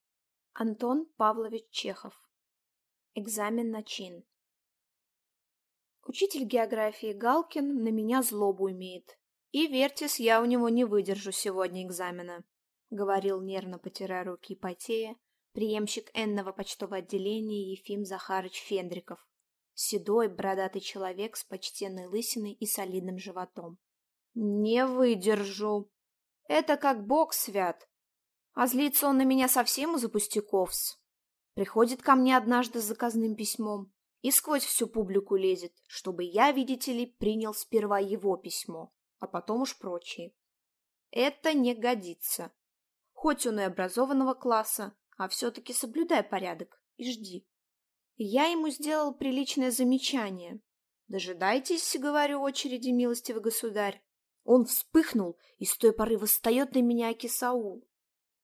Аудиокнига Экзамен на чин | Библиотека аудиокниг